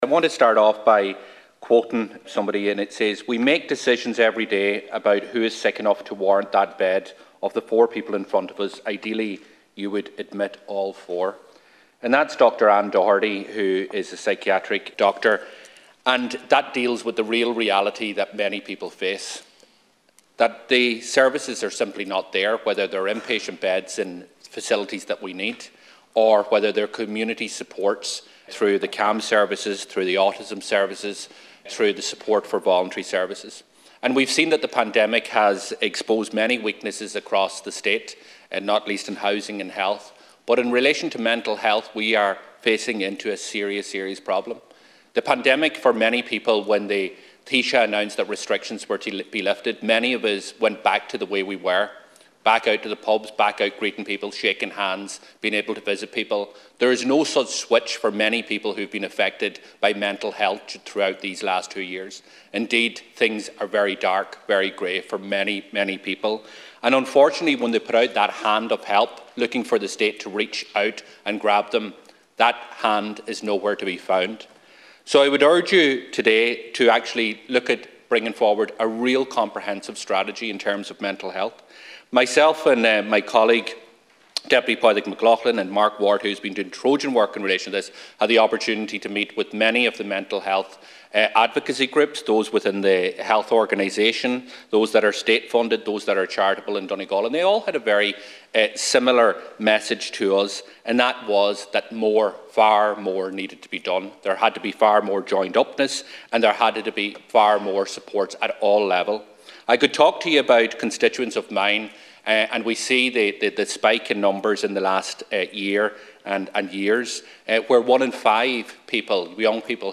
Speaking during statements in the Dail on Youth Mental Health, Deputy Pearse Doherty says the evidence is clear that much more needs to be done to support young people.